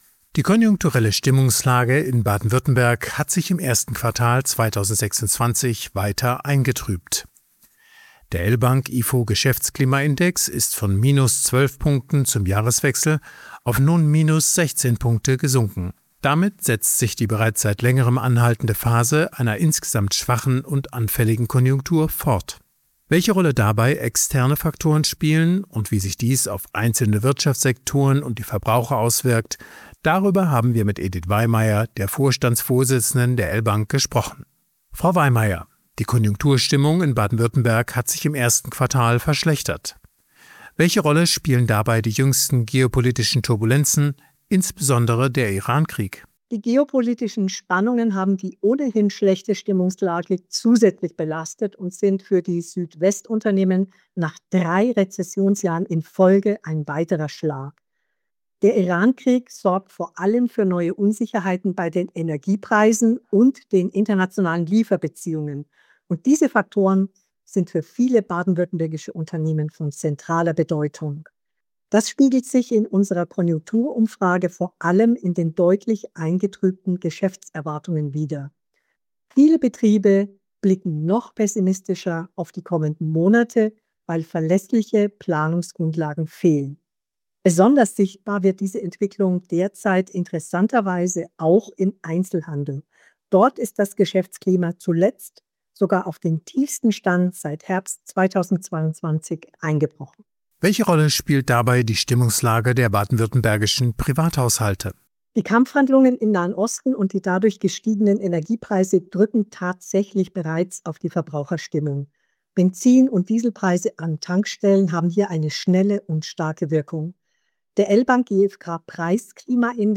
Konjunktur-Interview